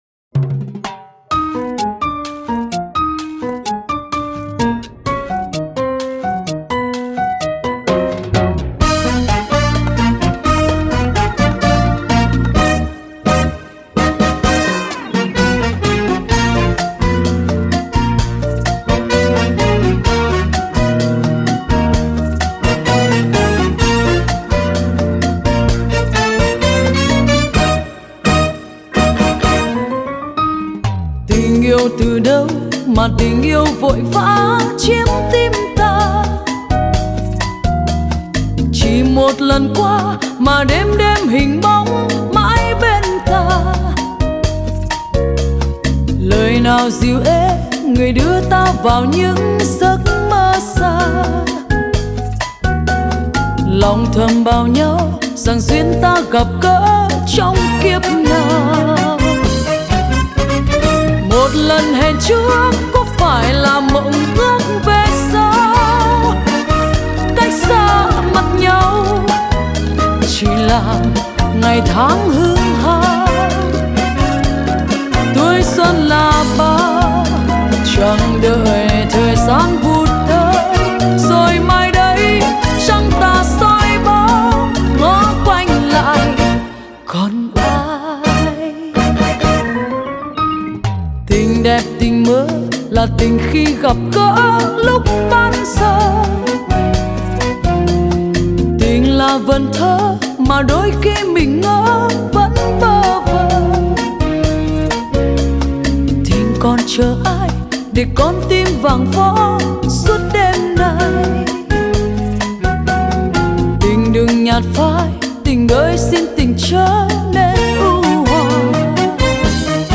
Nhạc vàng trữ tình